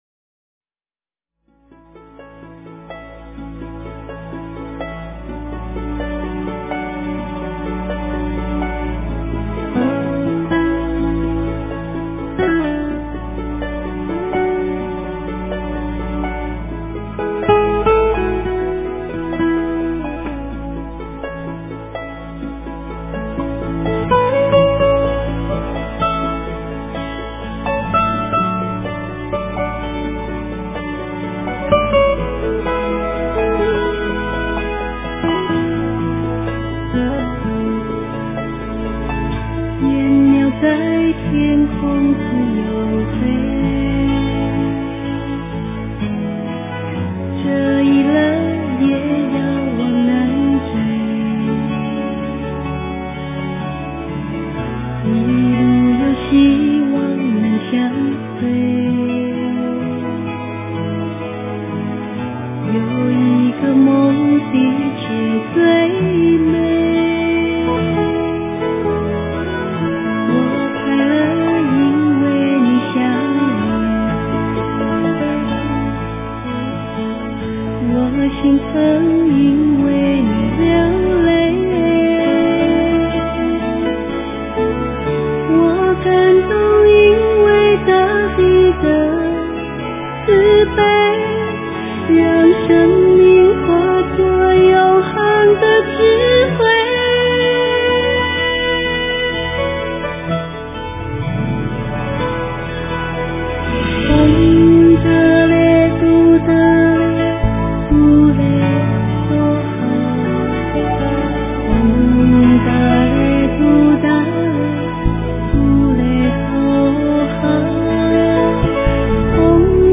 佛音 诵经 佛教音乐 返回列表 上一篇： 观音灵感真言(梦授咒